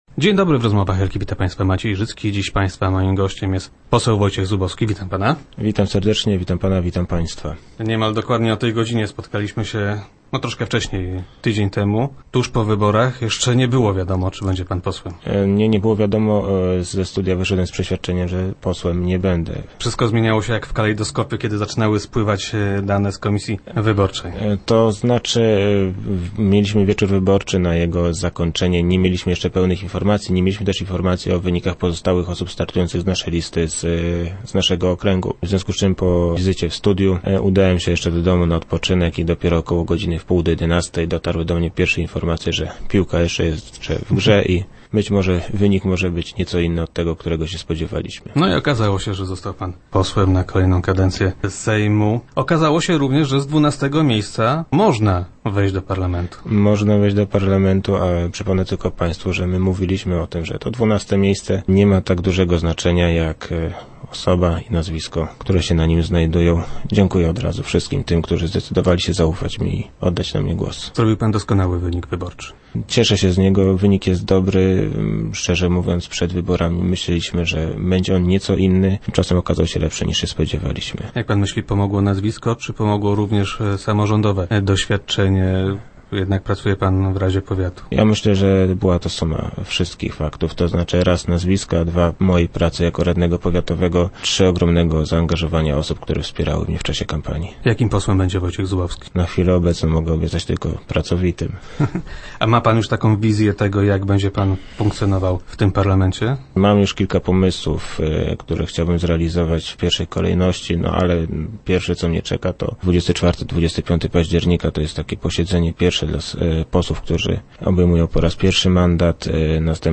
Wojciech Zubowski był gościem poniedziałkowych Rozmów Elki.